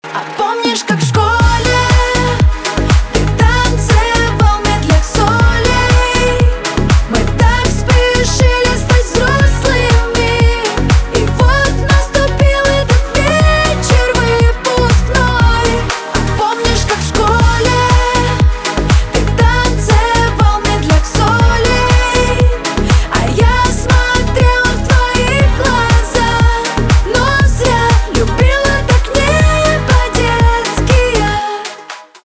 поп
грустные